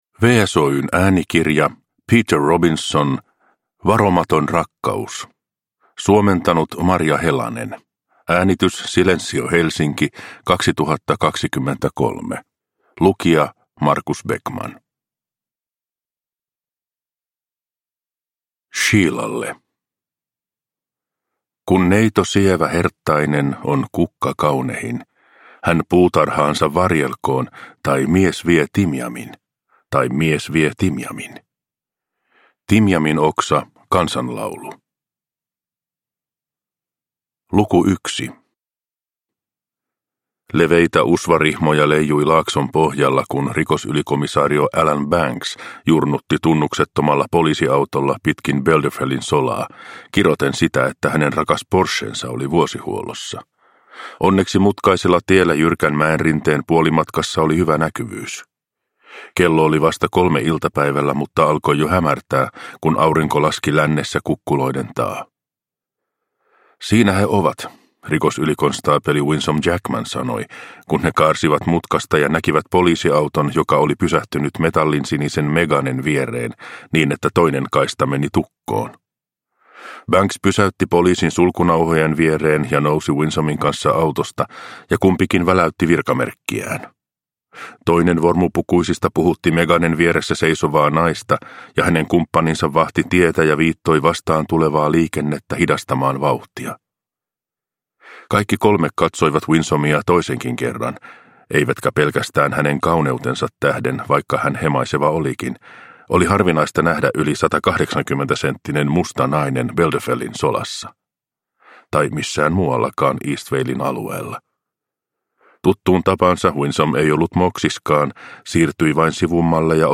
Varomaton rakkaus – Ljudbok – Laddas ner